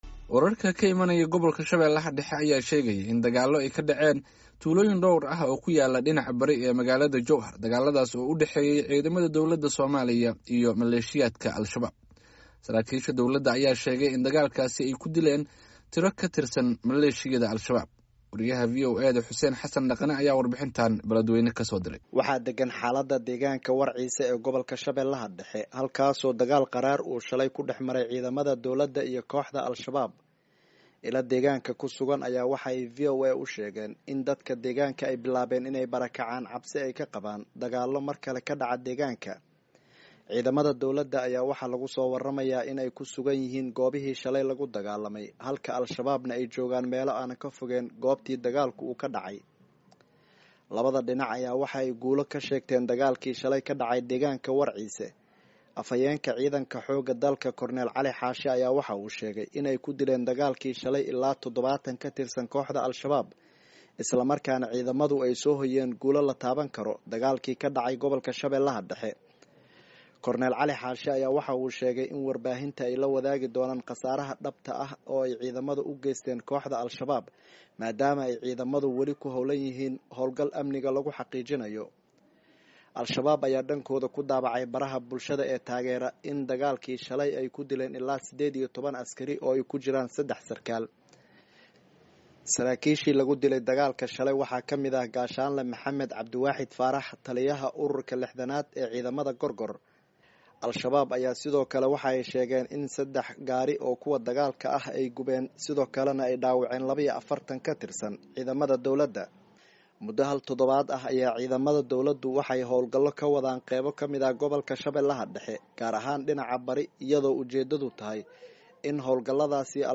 warbixintan ka soo diray Beledweyne.